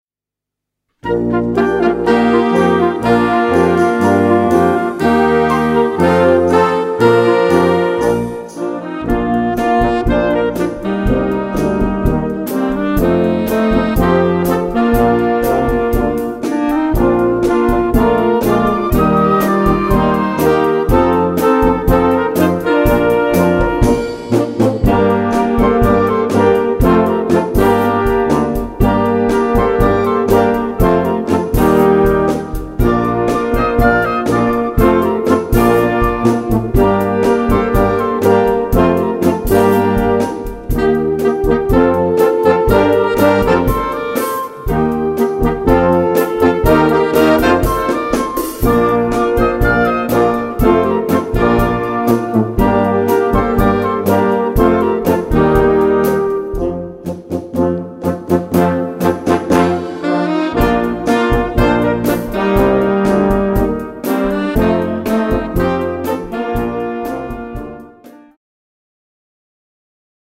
1:41 Minuten Besetzung: Blasorchester Zu hören auf